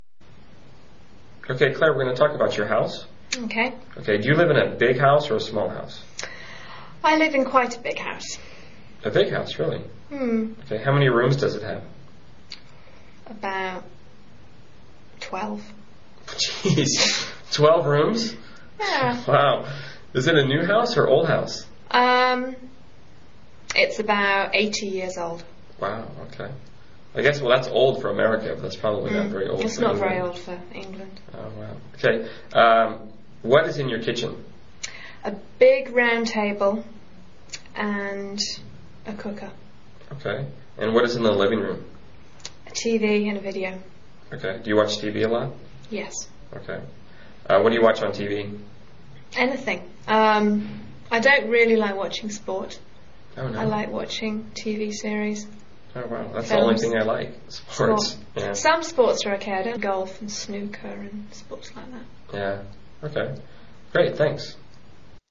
英语高级口语对话正常语速22:房间描述（MP3）